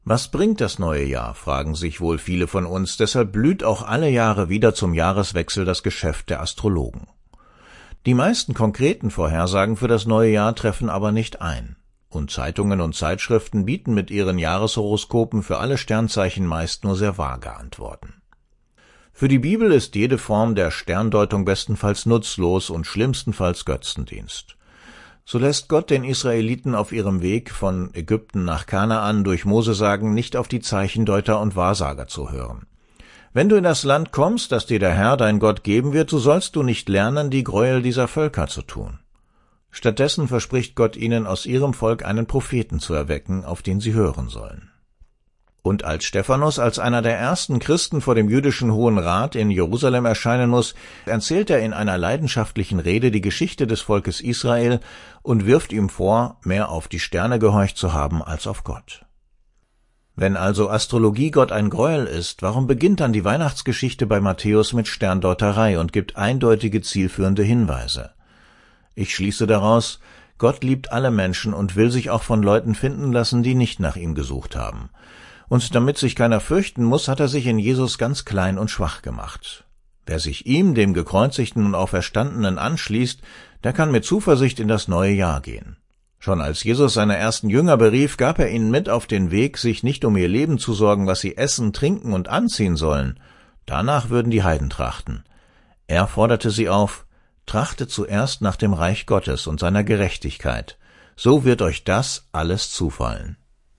Diesen Radiobeitrag